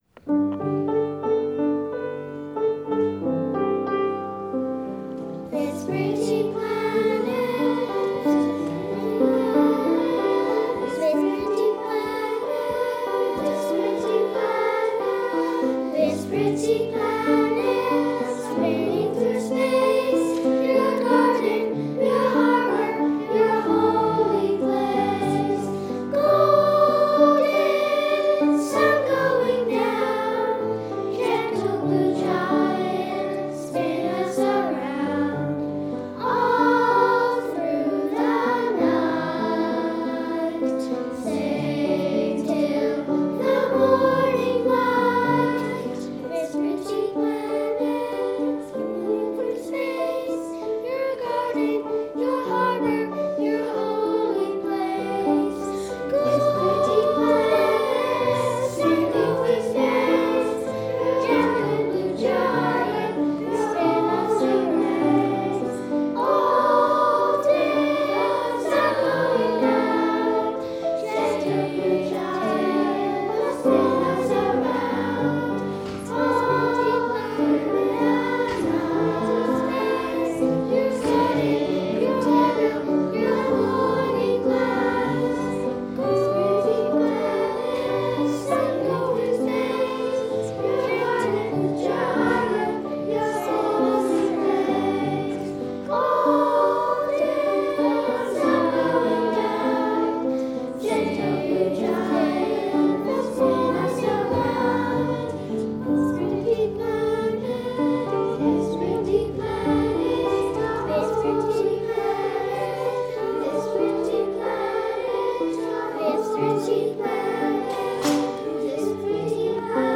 Recording Location: James Bay United Church, Victoria BC
Status: Raw, unedited
The 20-member children's chorus
128kbps Stereo